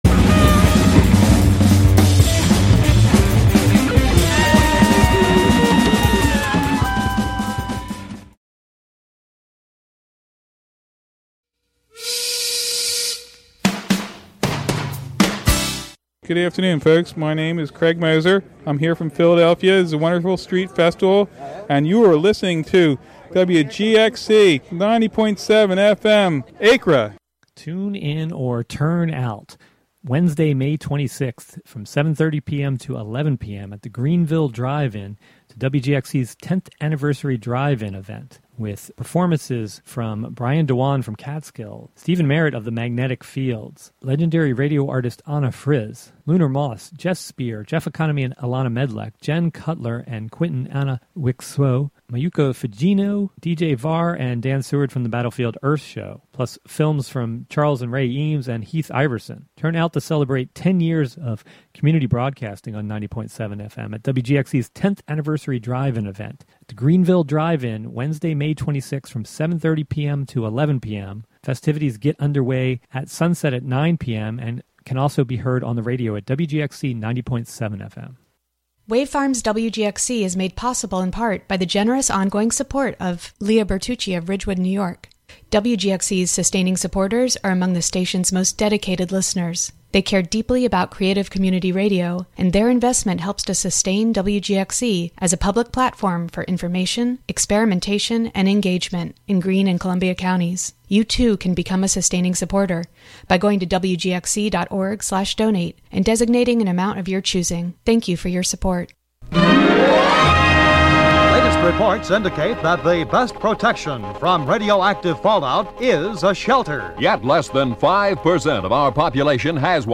We cover how to achieve richer queries in the personal experience database, explore the electromagnetic field of the heart, relationship impedance matching, imposter syndrome attenuation and navigating quantification of emotional risk as a function of ADHD. Wave Farmacy is a talk show where callers bring emotional/relationship/life problems to be discussed from an engineering perspective by engineers.